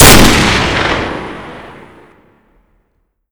WR_Fire.wav